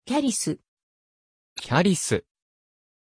Pronunciation of Charis
pronunciation-charis-ja.mp3